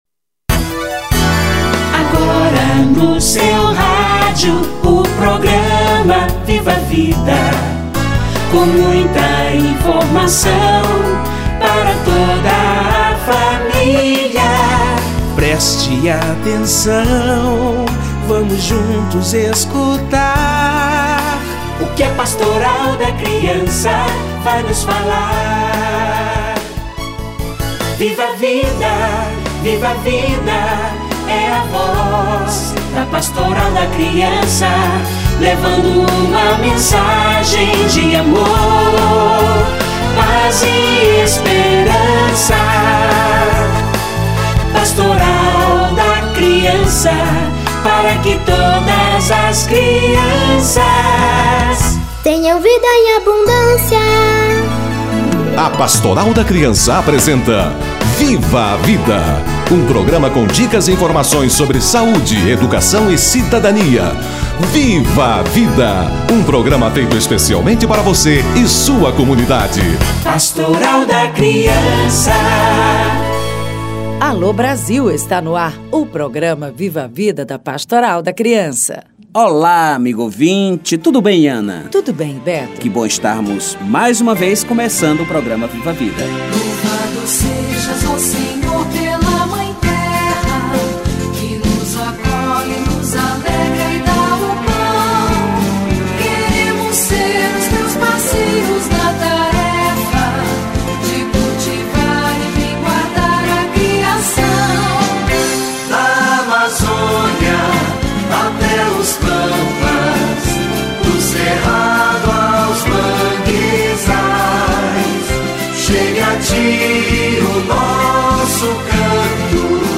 Campanha da Fraternidade 2017 - Entrevista